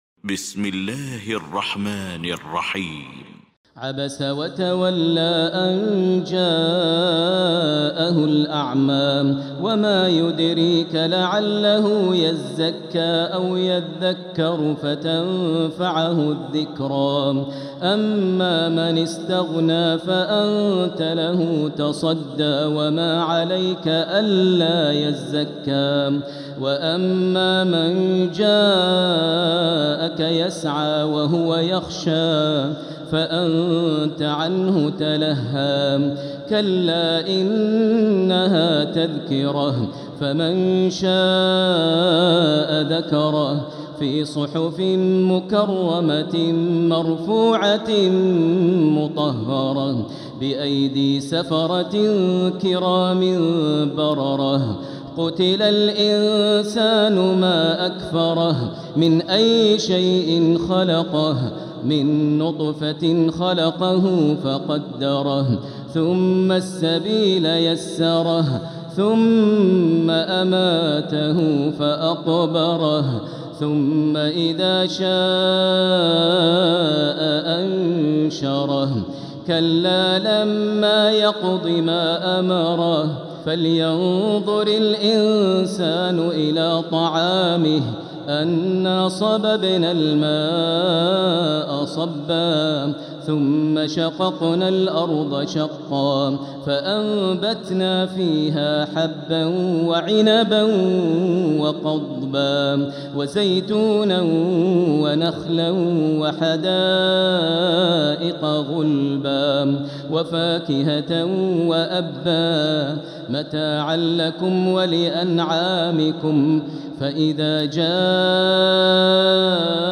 المكان: المسجد الحرام الشيخ: فضيلة الشيخ ماهر المعيقلي فضيلة الشيخ ماهر المعيقلي عبس